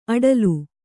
♪ aḍalu